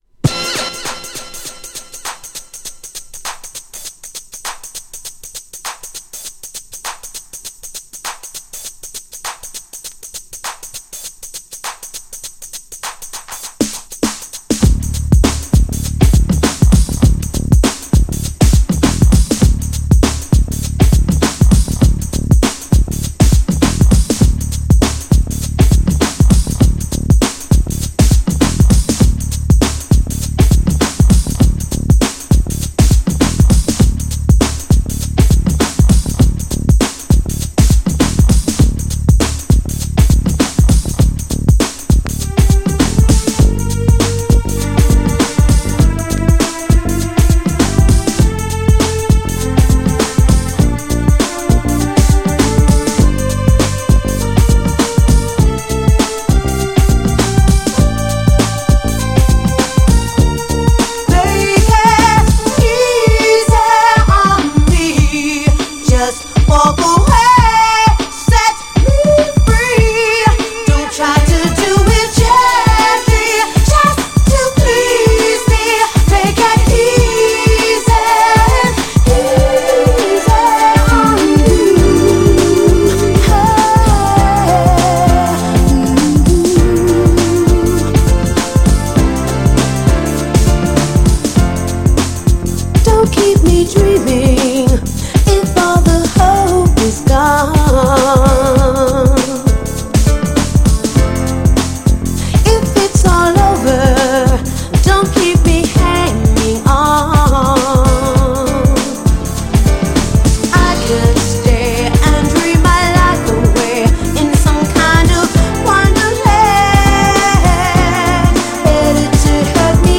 GENRE House
BPM 96〜100BPM